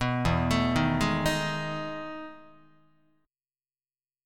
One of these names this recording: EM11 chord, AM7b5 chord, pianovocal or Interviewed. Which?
EM11 chord